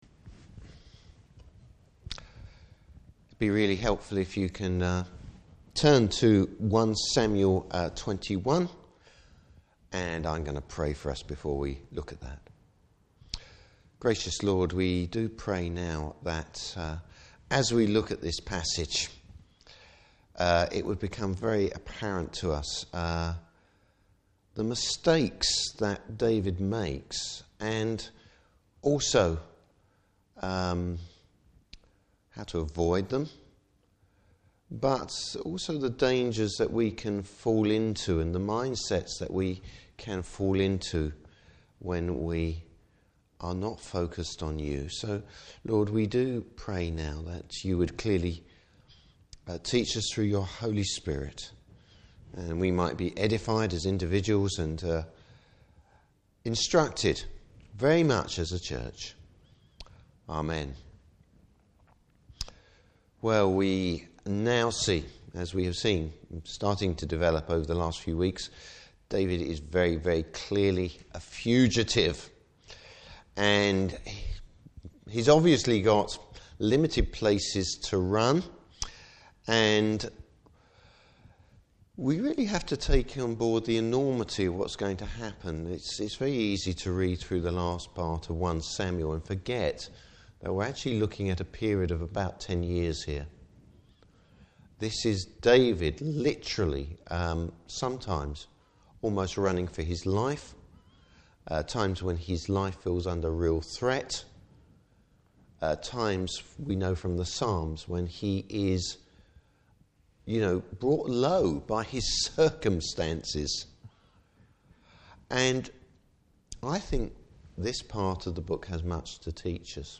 Service Type: Evening Service What happen when David didn’t consult the Lord!